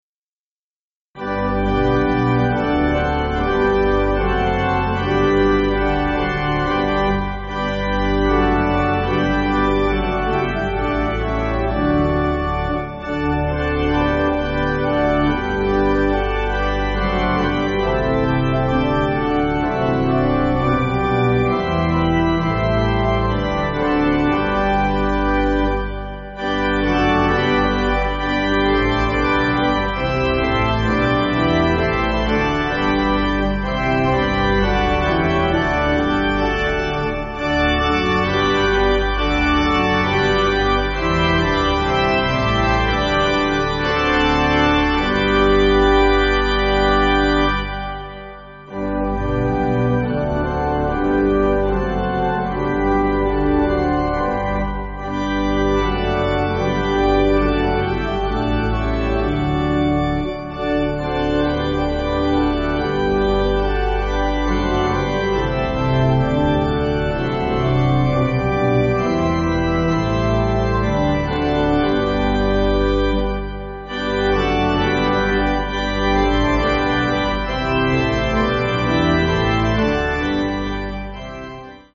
Organ
(CM)   3/G